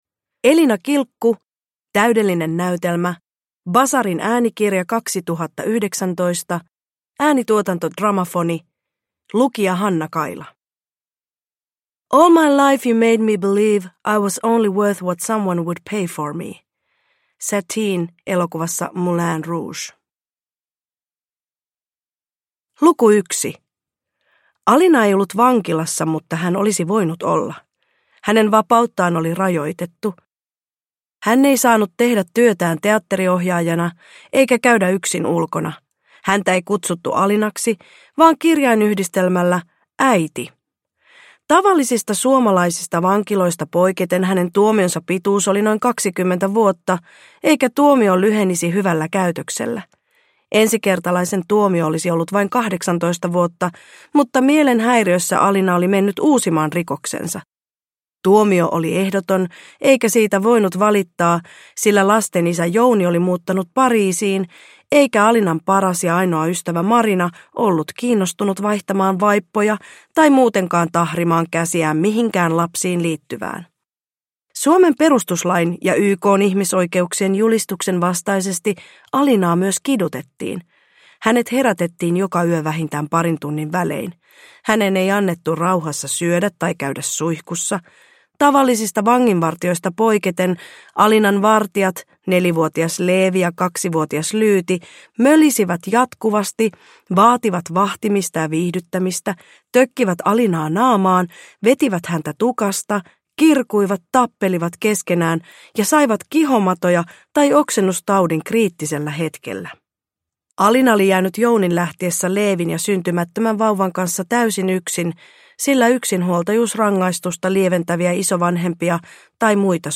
Täydellinen näytelmä – Ljudbok – Laddas ner